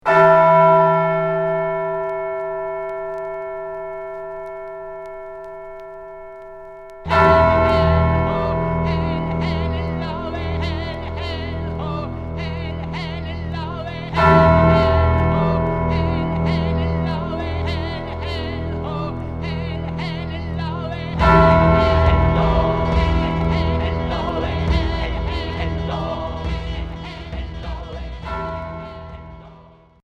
Gothique expérimental